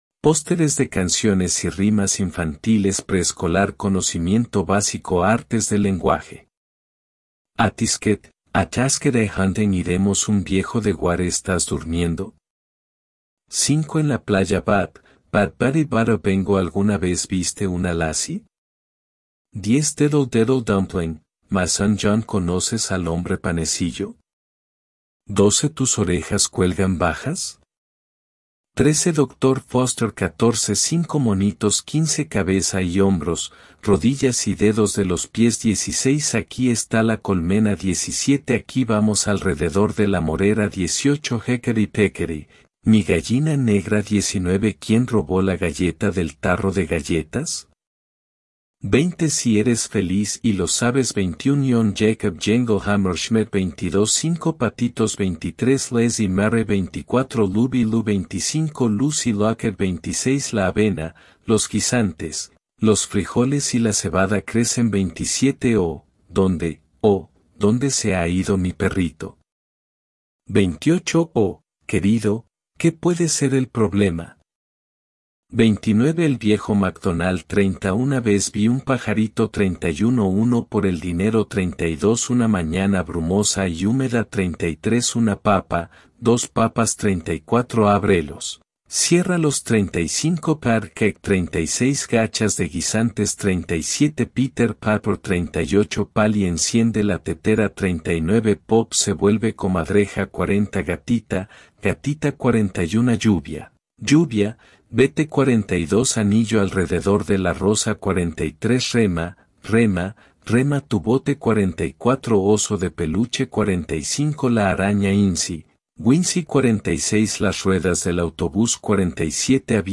123_PK_BB_NurseryRhymesSongs-CKF-FKB_sp.mp3